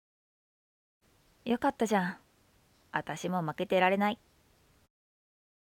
💜セリフ